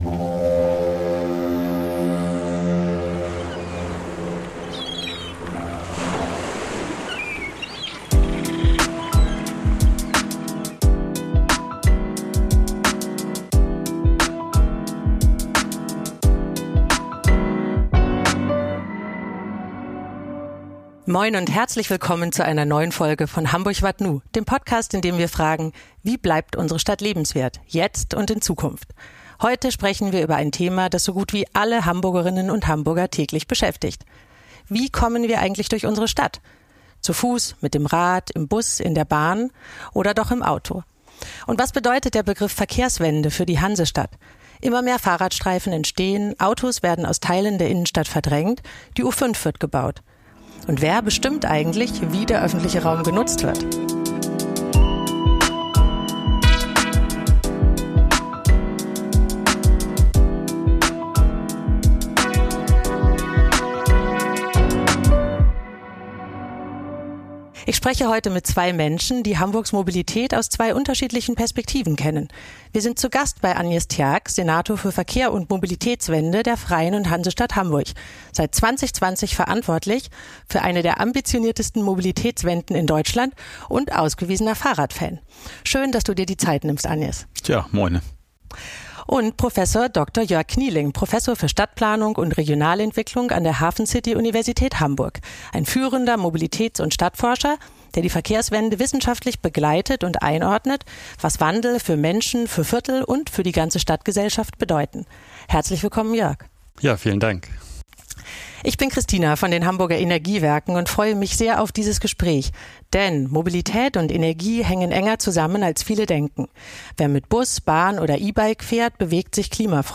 Ein Gespräch über Zielkonflikte, Tempo und Mut – und darüber, wie sich Hamburg Schritt für Schritt neu bewegt. Aufgenommen in der Verkehrsbehörde in der Hamburger Innenstadt.